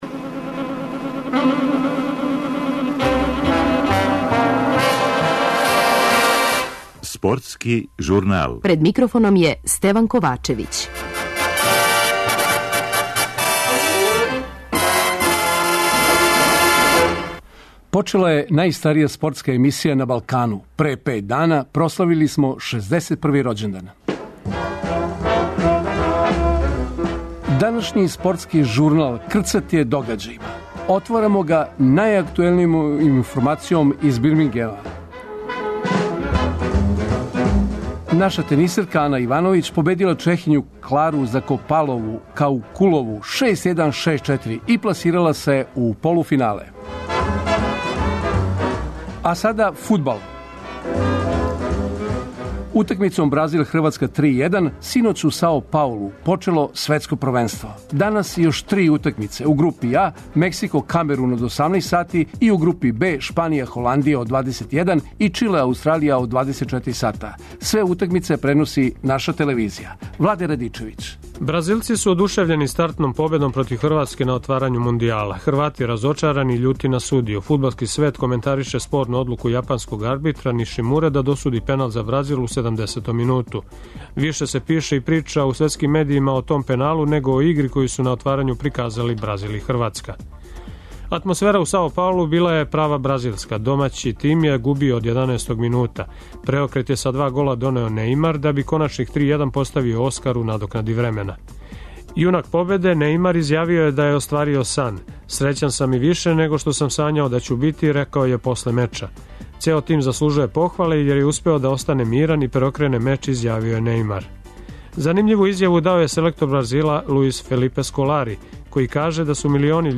Радио Београд 1